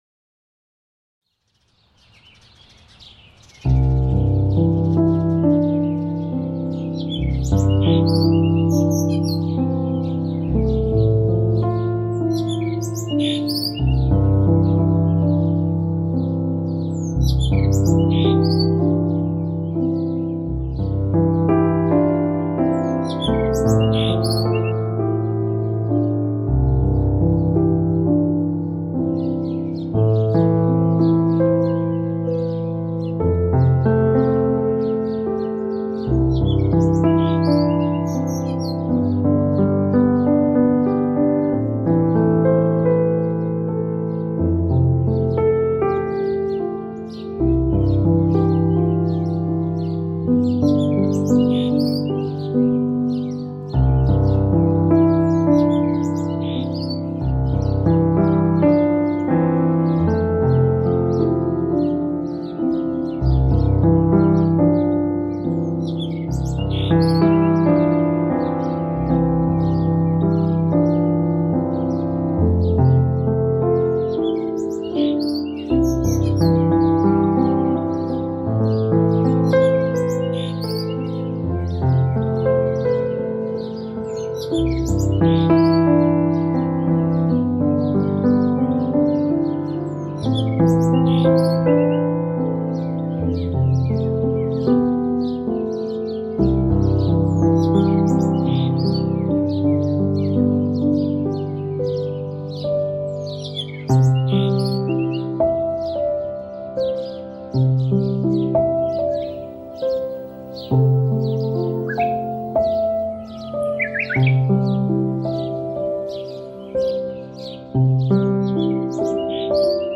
Kirschblüten Naturgeräusche & Vogelgesang | Frühling & Heilung